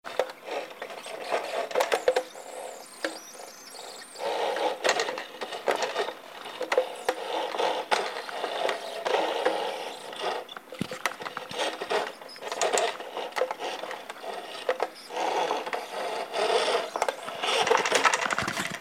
Strange noise when using FF on Turbo wheel
WheelNoise.mp3 - 368 KB - 297 views
You mean the sortoff high pitched noise?